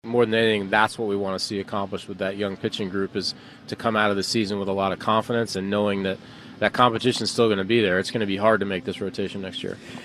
On The Ben Cherington Show on WCCS yesterday, the Pirates’ general manager said the team wants to give its young starting pitchers a chance to start games on the big league level while looking ahead to competing for a rotation spot in spring training